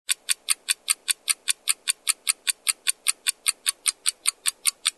Звуки таймера
5 секунд ожидания